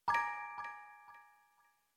Old Music Box 3
bonus-sound film-production game-development intro magic music-box mystic mystical sound effect free sound royalty free Music